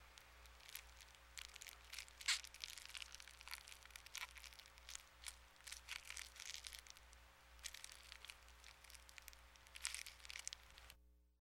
Звуки сколопендры - скачать и слушать онлайн бесплатно в mp3